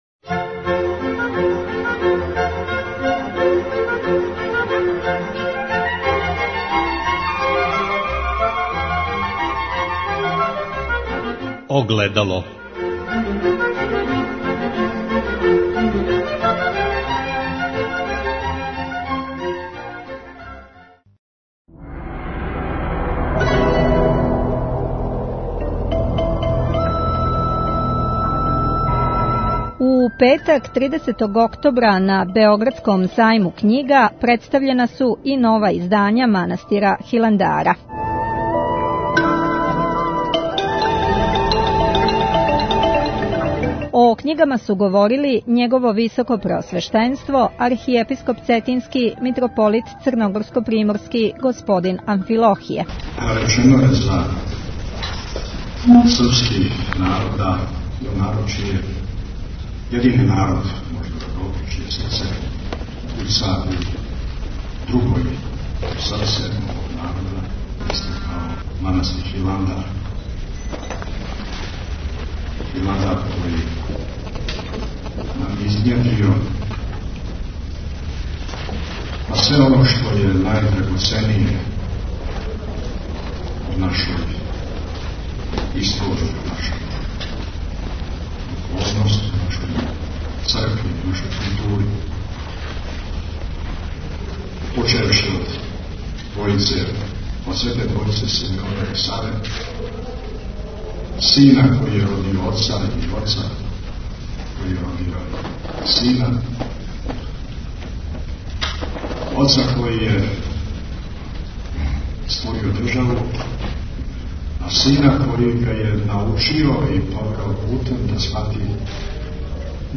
У петак 30. октобра на Сајму књига у Београду представљена су нова издања манастира Хиландара, а о књигама су говорили: